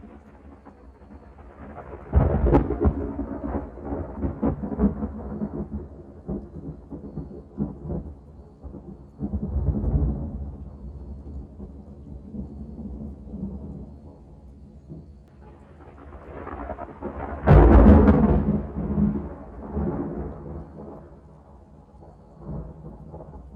Thunder_00.wav